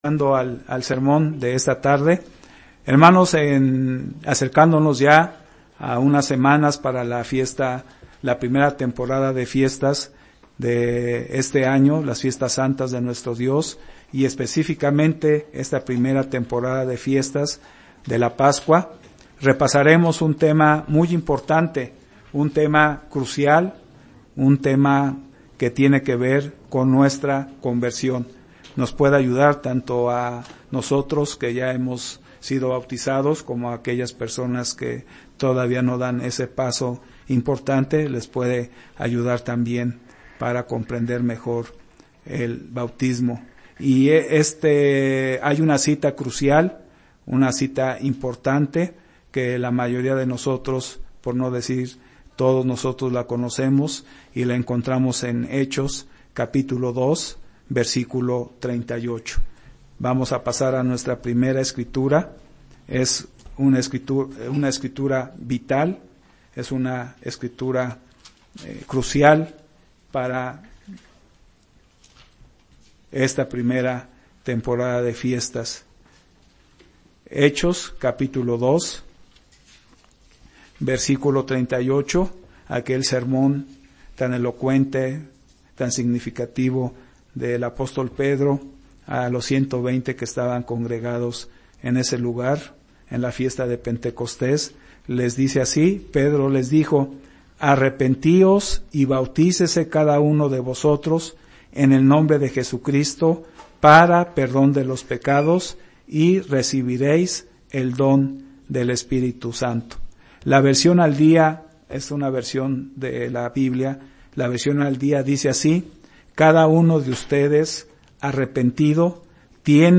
Sermones
Given in Ciudad de México